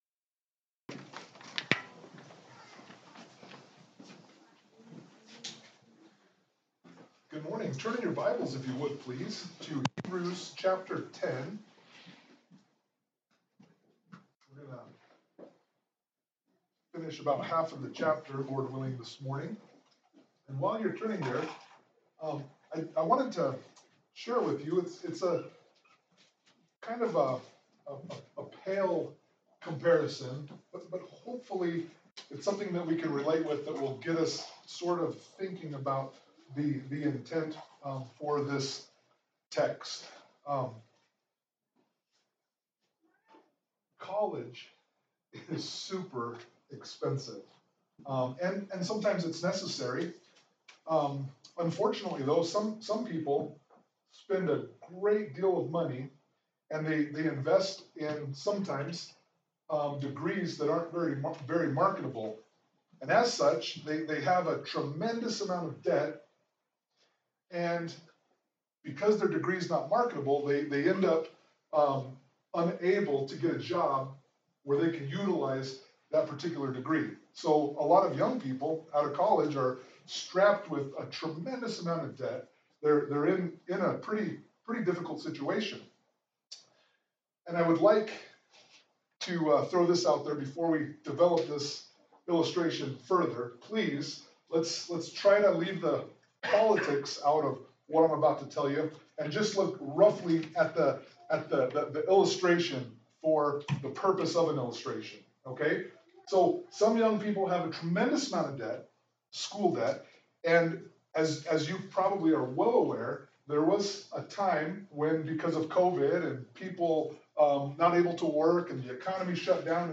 Hebrews 10:1-18 Service Type: Sunday Morning Worship « Hebrews Chapter 9